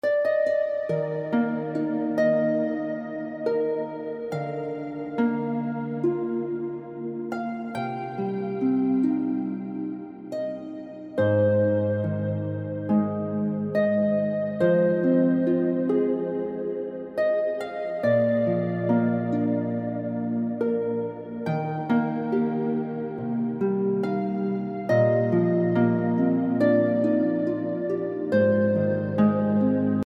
Heavenly Harp Music Tag